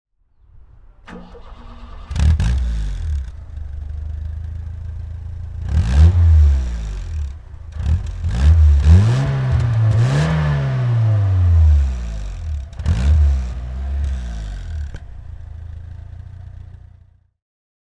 Motorsounds und Tonaufnahmen zu Pininfarina Azzurra Spider Fahrzeugen (zufällige Auswahl)
Pininfarina Spidereuropa (1985) - Starten und Leerlauf